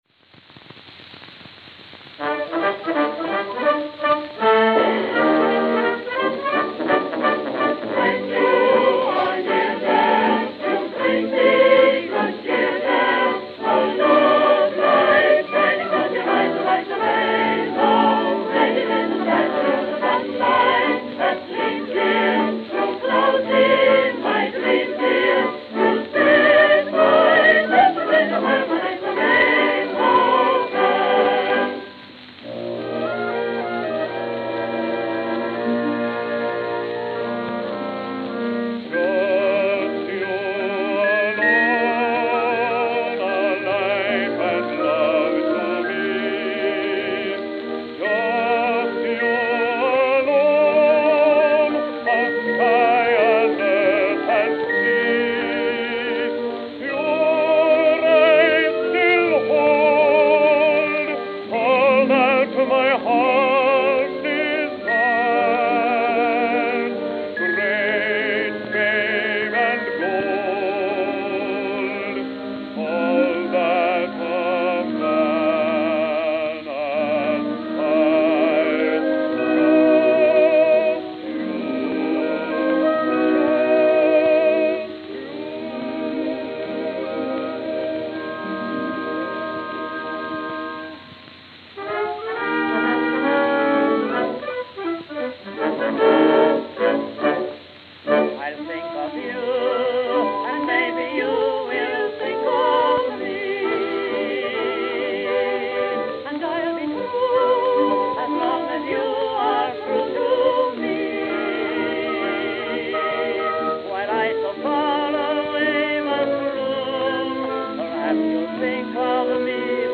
Camden, New Jersey Camden, New Jersey